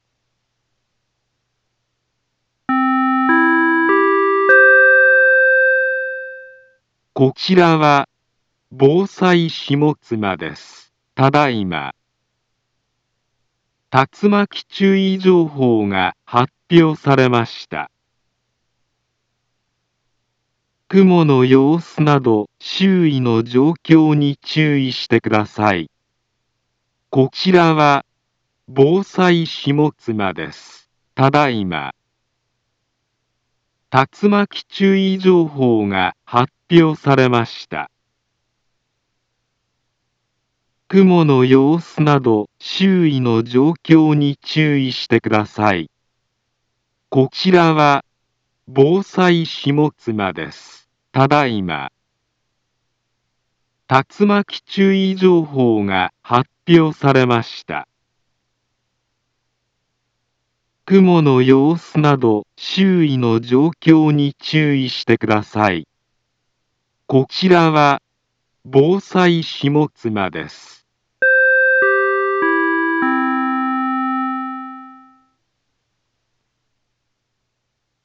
Back Home Ｊアラート情報 音声放送 再生 災害情報 カテゴリ：J-ALERT 登録日時：2023-07-11 19:05:02 インフォメーション：茨城県北部、南部は、竜巻などの激しい突風が発生しやすい気象状況になっています。